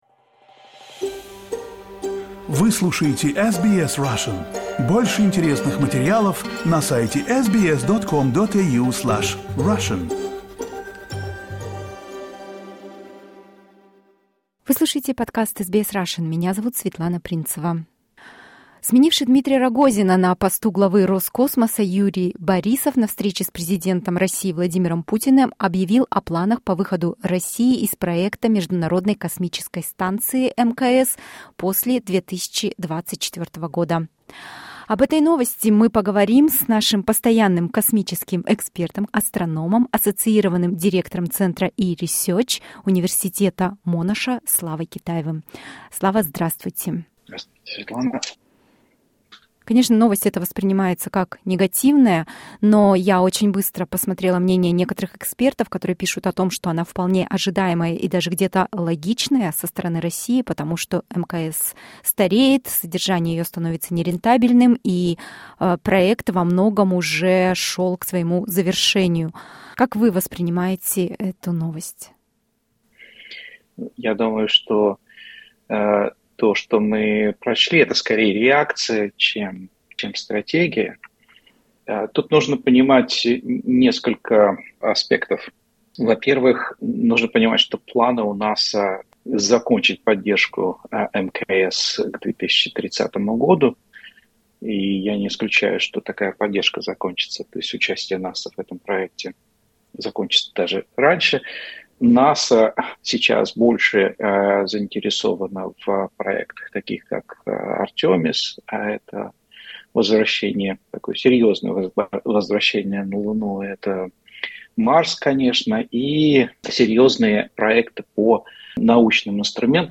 Moscow has announced it will withdraw it's involvement with the International Space Station after 2024. What does it mean for the future of Russia's space industry? Interview